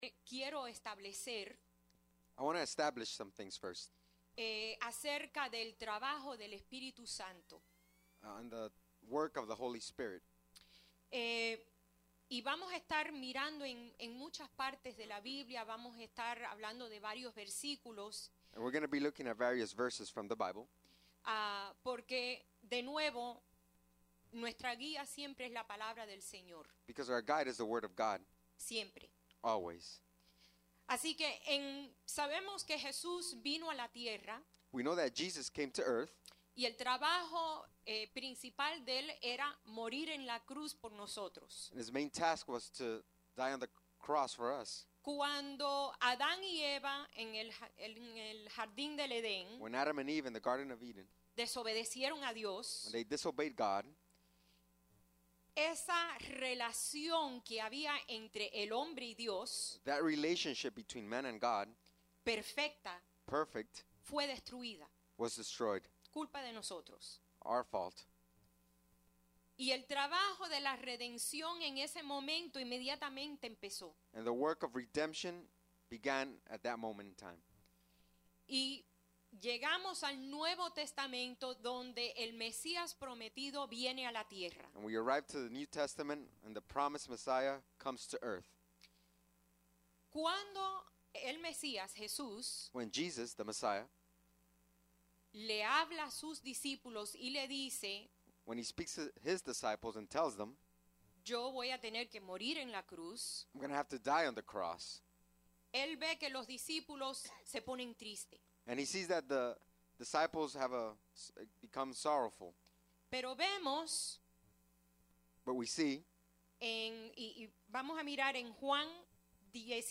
Sermons | Iglesia Centro Evangelico